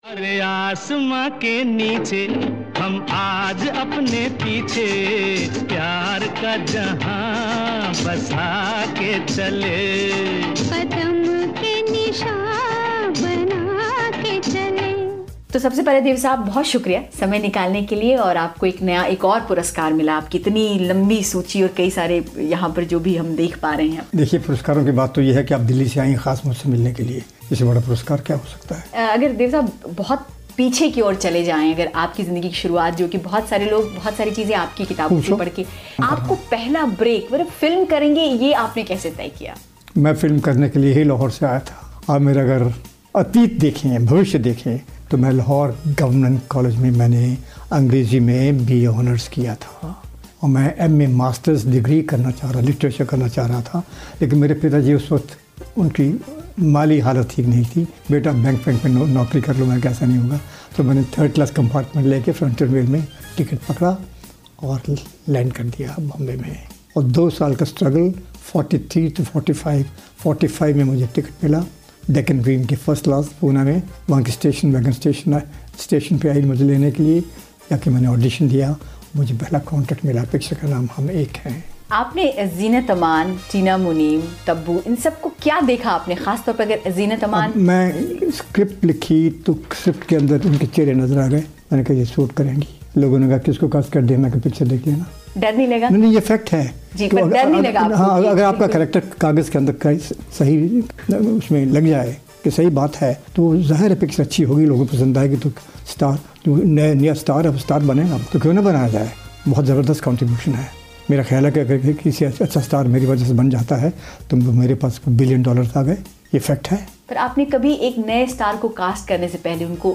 मशहूर निर्माता निर्देशक दिवंगत देव आनंद के जन्मदिन पर बीबीसी के ख़ज़ाने से उनसे की गई एक ख़ास बातचीत